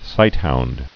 (sīthound)